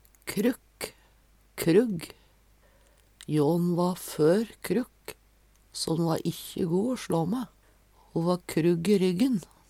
Tilleggsopplysningar gradbøygning Sjå òg gag (Veggli) gruv (Veggli) Høyr på uttala